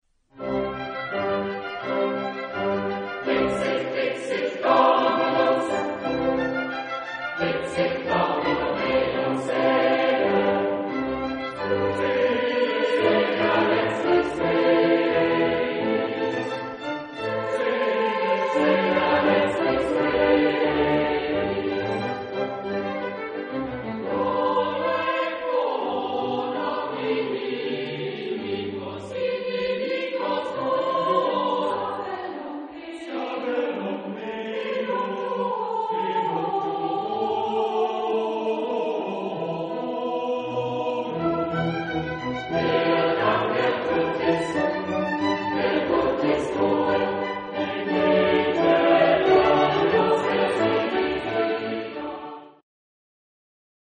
Género/Estilo/Forma: Sagrado ; Salmo ; Barroco
Carácter de la pieza : rápido
Tipo de formación coral: SATB  (4 voces Coro mixto )
Solistas : SATB  (4 solista(s) )
Instrumentación: Pequeño ensamble instrumental  (8 partes instrumentales)
Instrumentos: Oboe (2) ; Corno (2) ; Violín (2) ; Viola (1) ; Contrabajo (1) ; Organo (1)
Tonalidad : do mayor